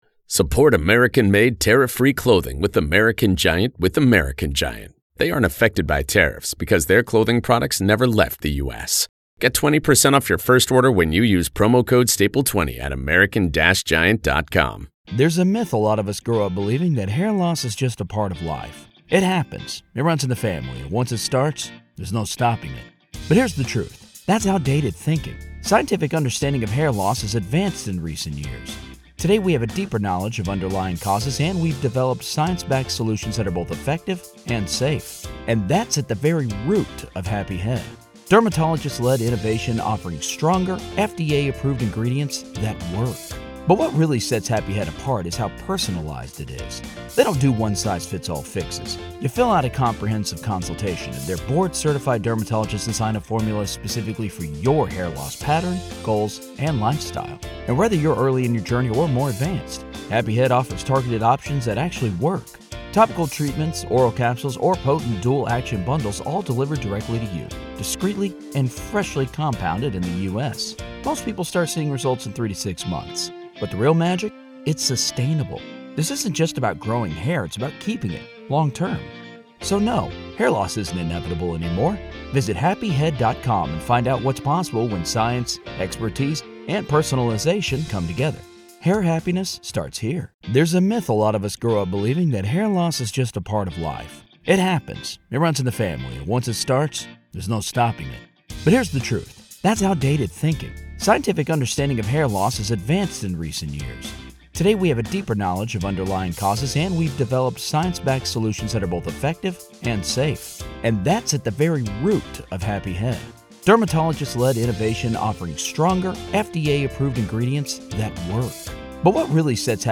The conversation also touches on the possibility of introducing a third-party suspect, a theory that wasn’t fully explored in the initial trial.